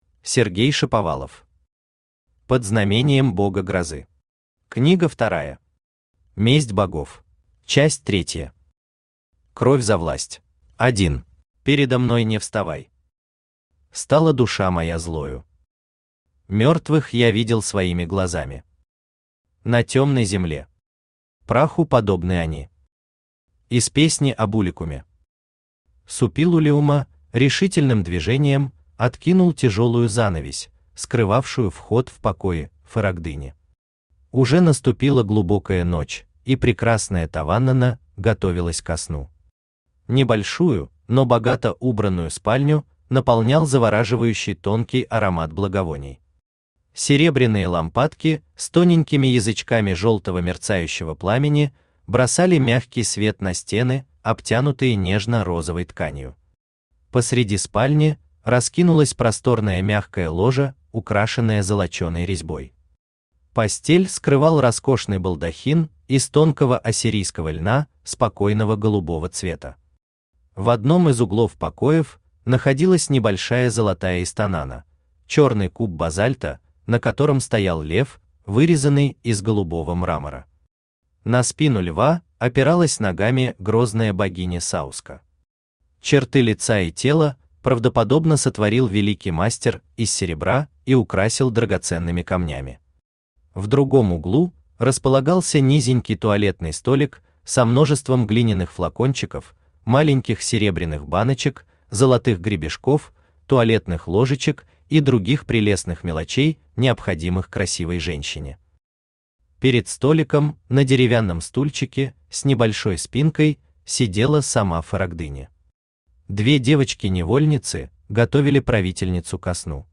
Месть Богов Автор Сергей Анатольевич Шаповалов Читает аудиокнигу Авточтец ЛитРес.